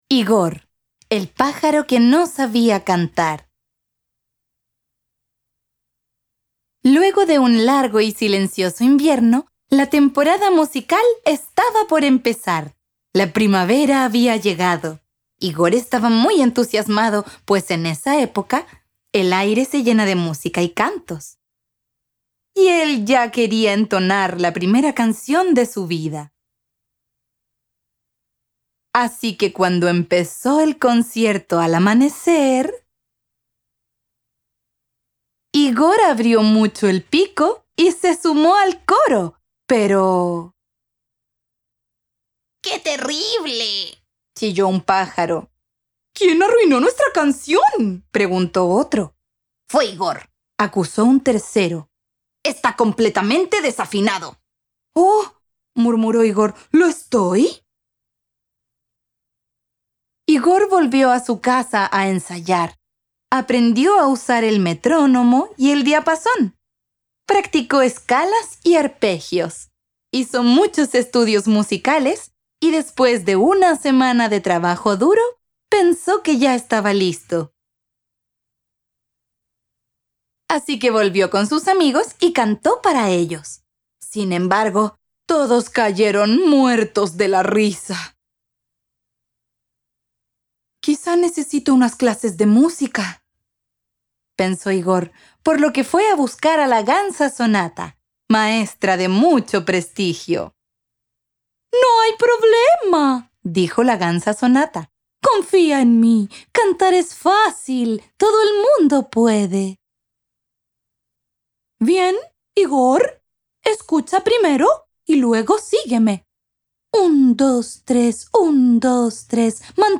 Audiolibro - Extracto Tomo 2
Videos y Audiocuentos